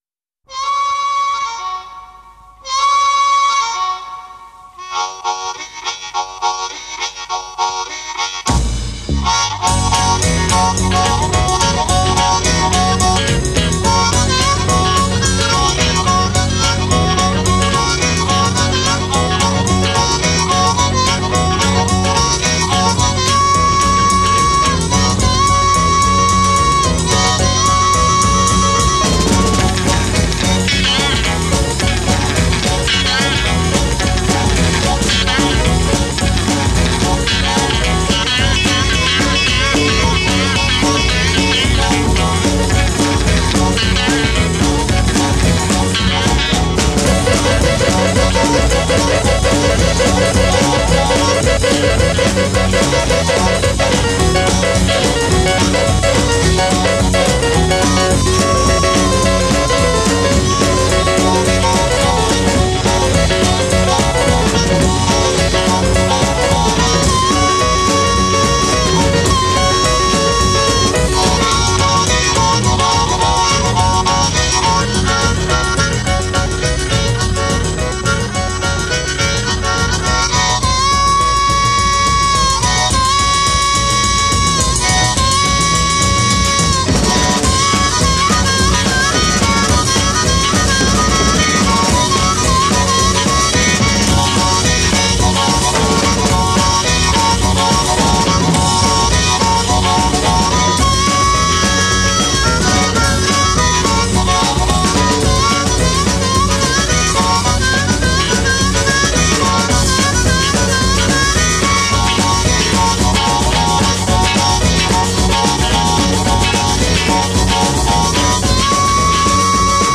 harmonica
guitar
piano
bass
drums
Intro Harmonica solo imitates train whistle.
Verse One-chord groove with various solos.